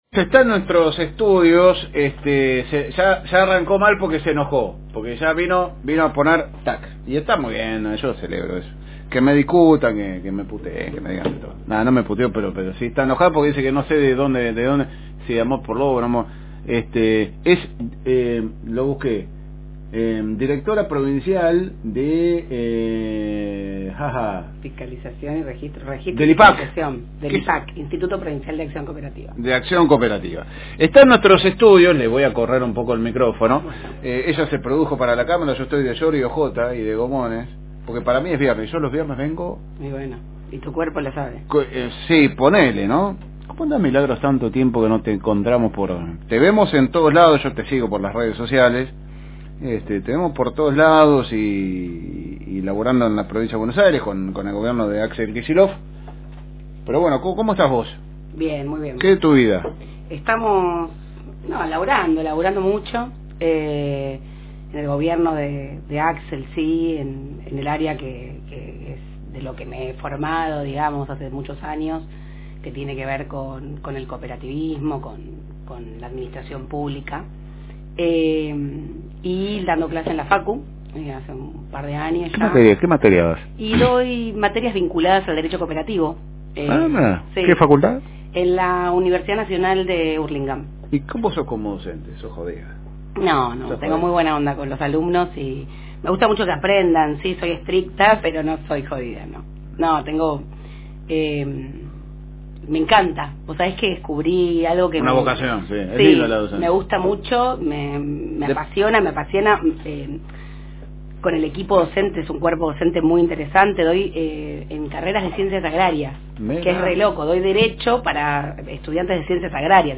Paso por los estudios de la FM Reencuentro la abogada, docente, militante política y hoy Directora Provincial del Instituto Provincial de Asociativismo y Cooperativismo de la pcia. de Buenos Aires Milagro Moya.